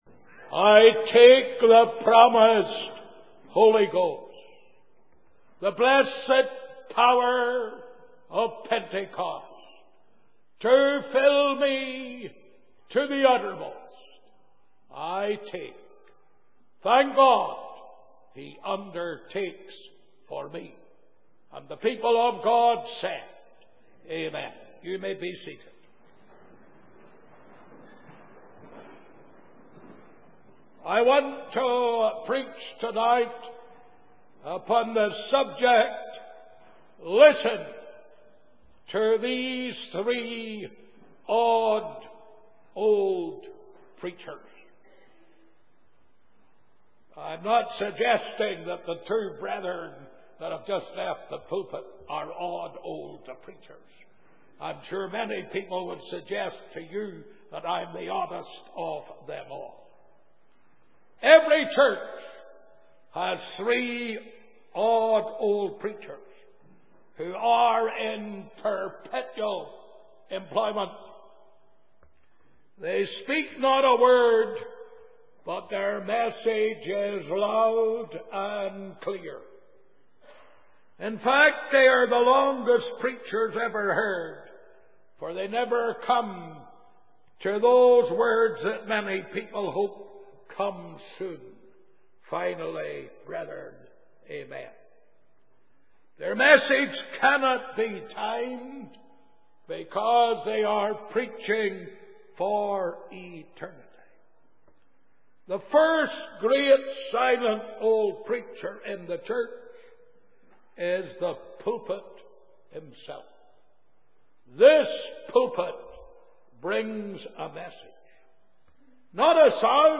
In this sermon, the preacher emphasizes the importance of giving and the attitude with which we should approach it. He highlights the story of Jesus observing the gifts being given at the collection plate, particularly focusing on a poor widow who gave all she had.